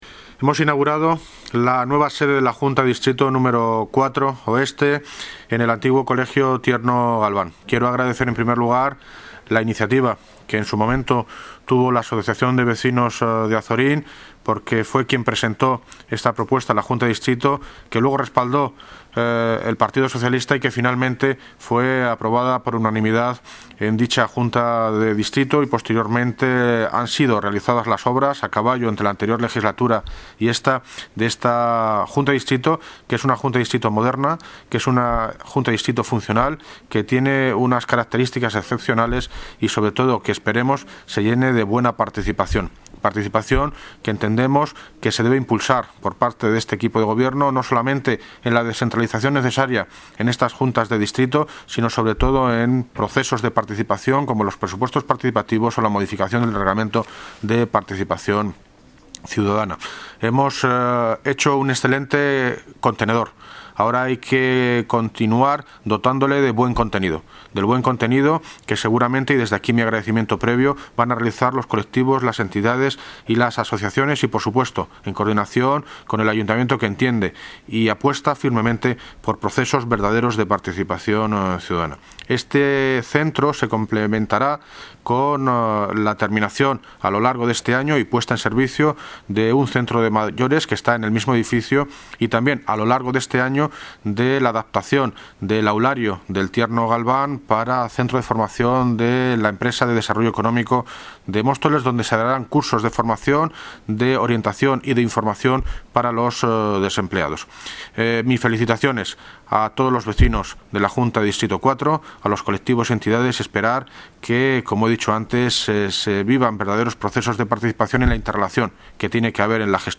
Audio - David Lucas (Alcalde de Móstoles) sobre la inauguración de la Junta de Distrito 4 - Oeste